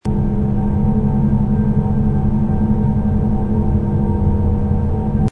airport_lobby_amb_loop.mp3